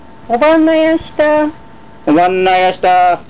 この町のことばをお聴きいただけます